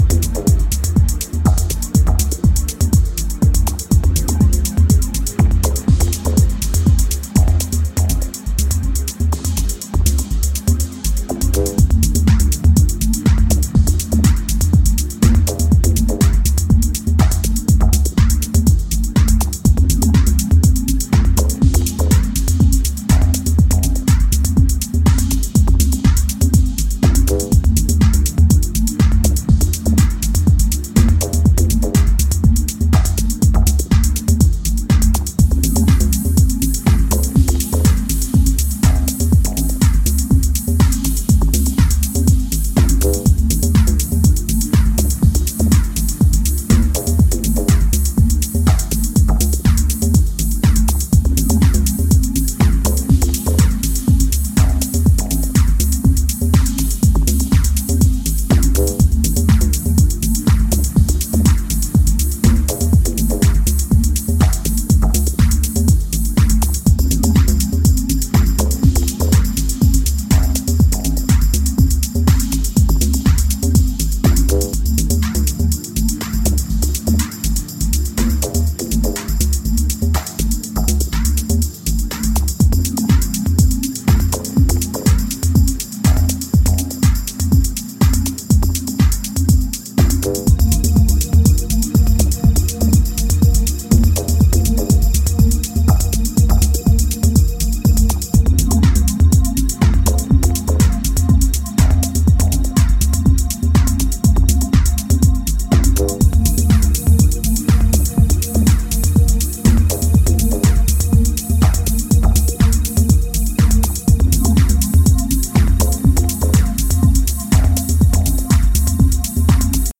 Electro House Techno